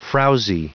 Prononciation du mot frowsy en anglais (fichier audio)
Prononciation du mot : frowsy